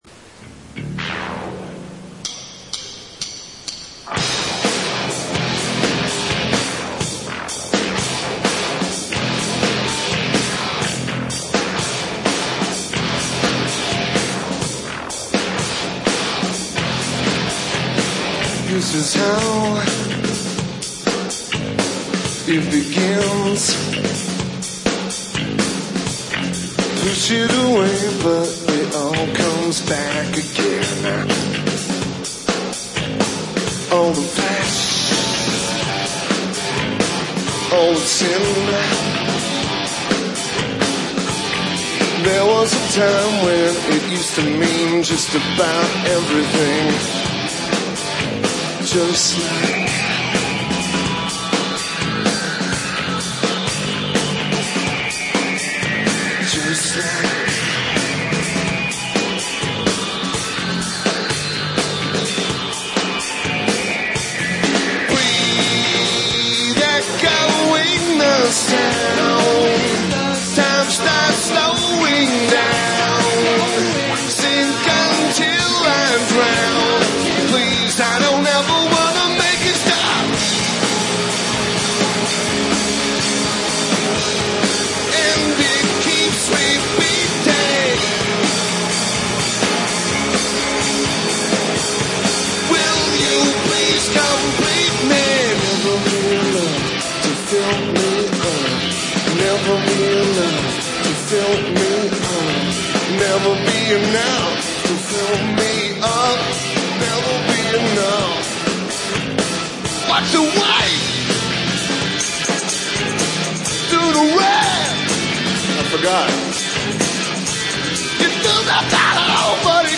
Key Club at Morongo Casino (Spiral Only Show)